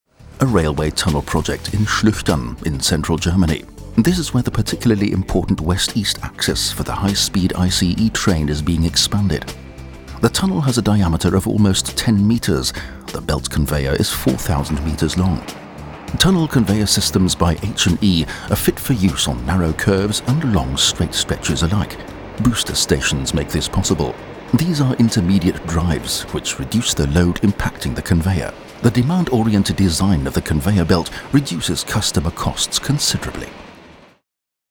Profi - Sprecher deutsch - englisch (britisch).
Sprechprobe: Werbung (Muttersprache):
Professional Voice Over Talent in english (british) and german (both mother-tongue, both completely free of accent).